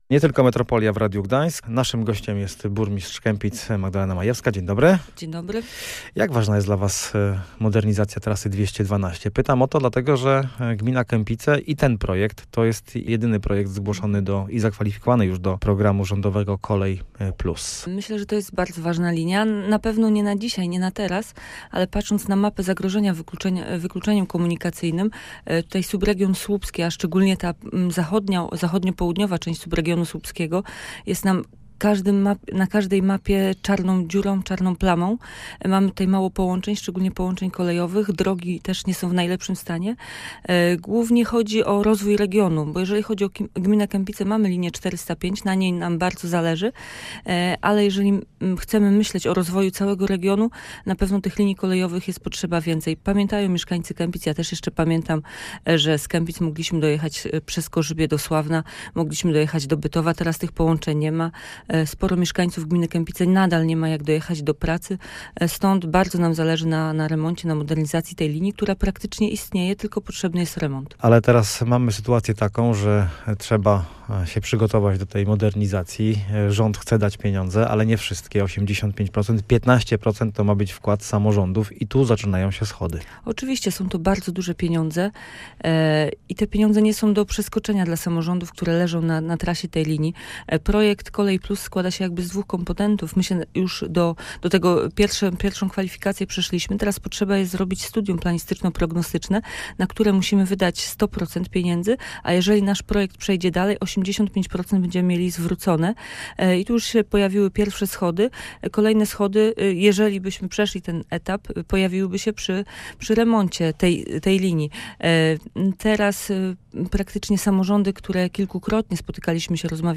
O udziale w tym projekcie mówił starosta słupski Paweł Lisowski.